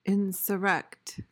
PRONUNCIATION:
(in-suh-REKT)